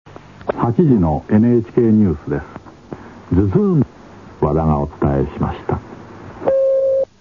つぎはぎニュース
大昔のラヂヲ番組、タモリのオールナイトニッポンでオンエアされたモノです。
ソースは２０年程前のエアチェックテープです(^^;